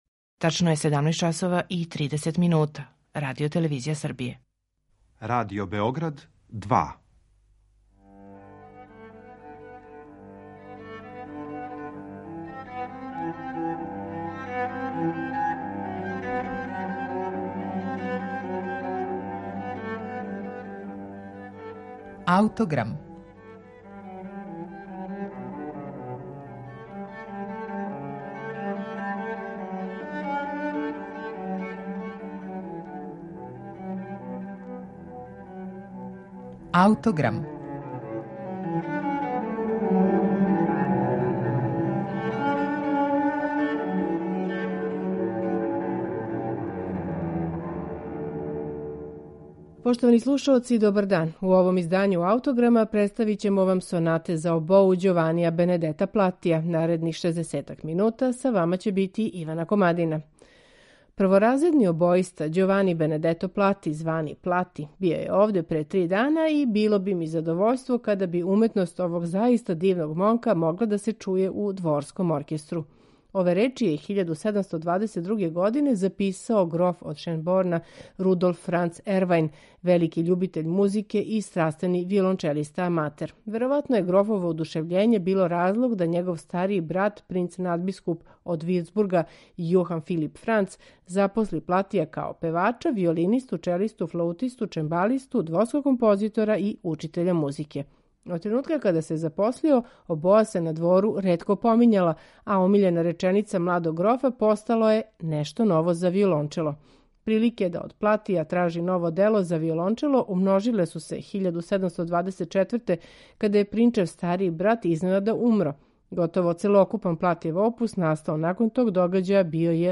Ђовани Плати: Сонате за обоу
У данашњем Аутограму представићемо Платијеве сонате за обоу, у интерпретацији чланова ансамбла Cordia, који свирају на оригиналним инструментима из 18. века.